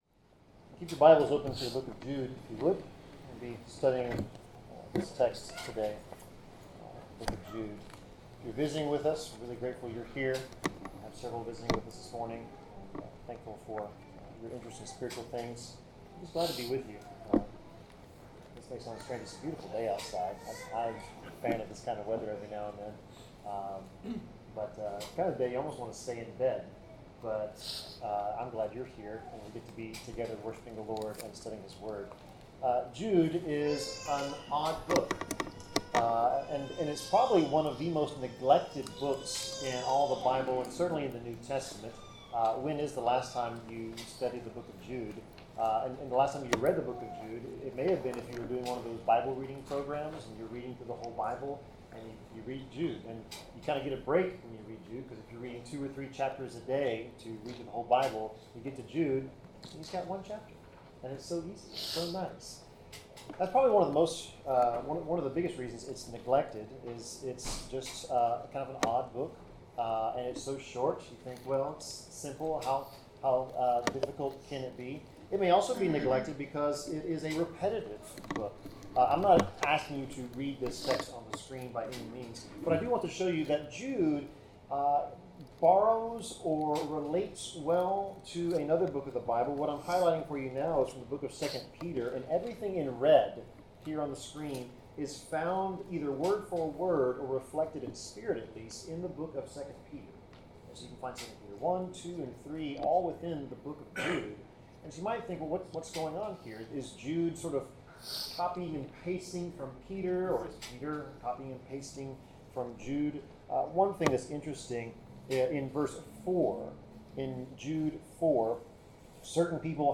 Due to unexpected technical difficulties, only part of this lesson is available.
Passage: Jude 1-25 Service Type: Sermon Due to unexpected technical difficulties